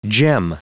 Prononciation du mot gem en anglais (fichier audio)
Prononciation du mot : gem